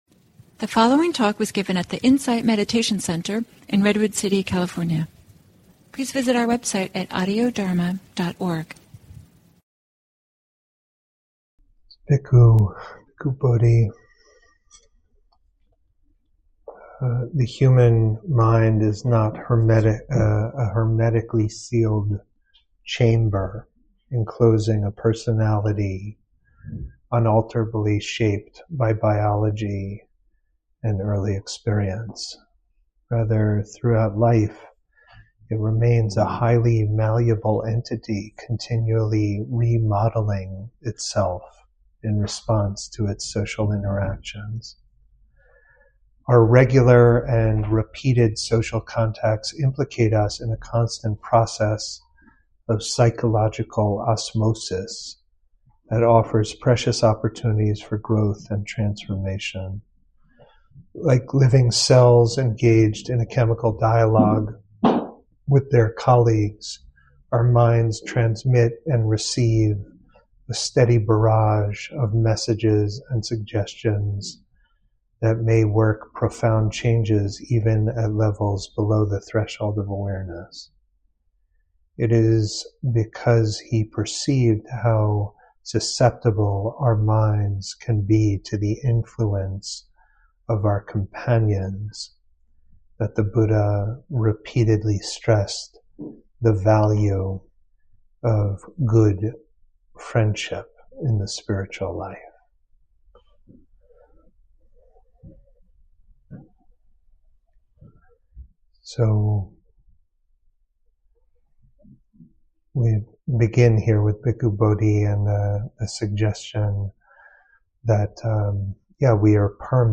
This talk